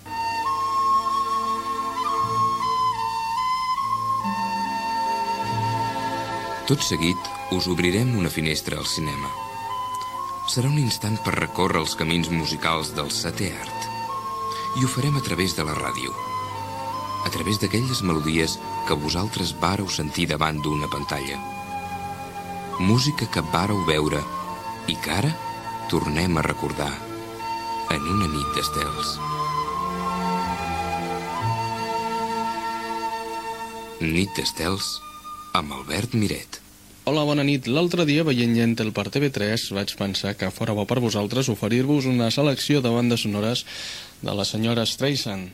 Careta del programa i presentació inicial
Musical
FM